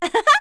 Juno-Vox-Laugh.wav